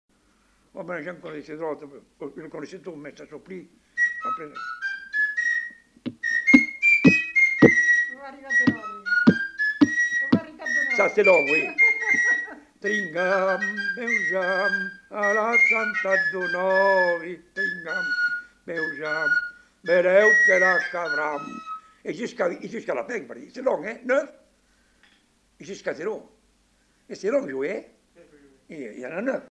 Aire culturelle : Bazadais
Département : Gironde
Genre : chanson-musique
Type de voix : voix d'homme
Production du son : fredonné
Instrument de musique : flûte à trois trous